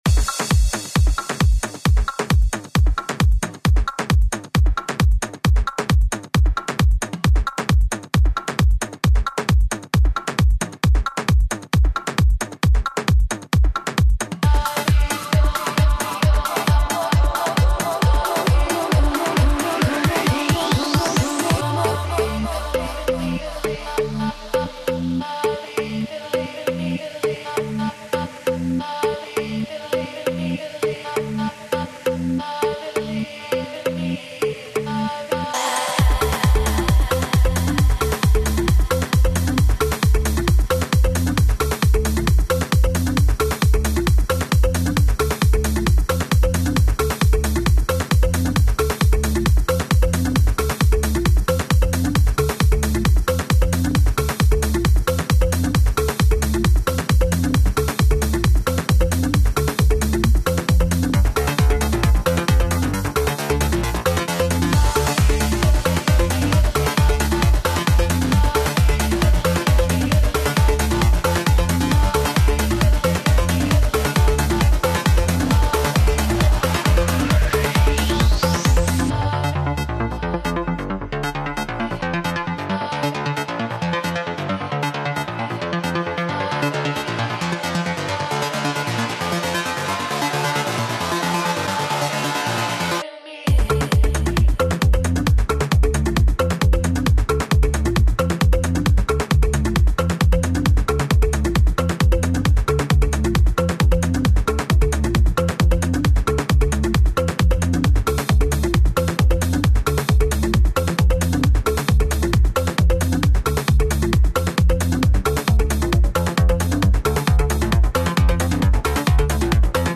Назад в ¤Super / Club / Dance¤
Жанр:Dance